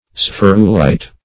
Search Result for " spherulite" : The Collaborative International Dictionary of English v.0.48: Spherulite \Spher"u*lite\, n. [Cf. F. sph['e]rulite.]